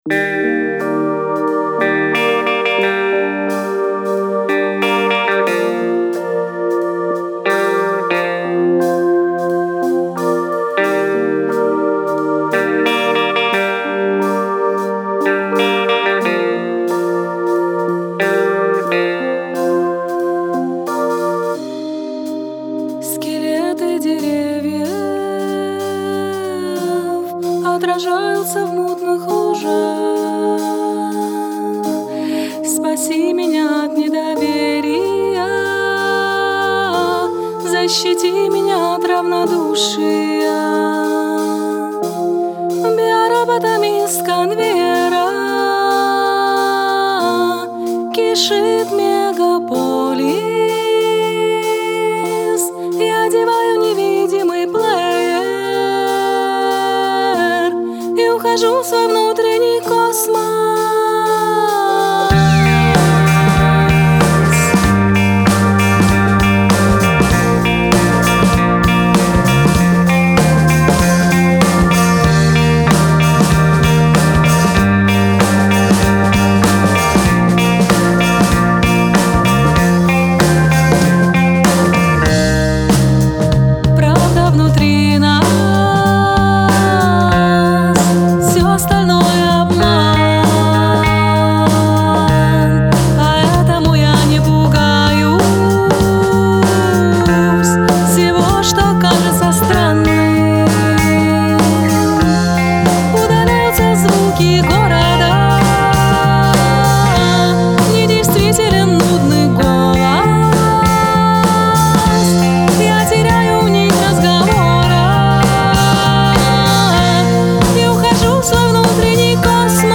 московская группа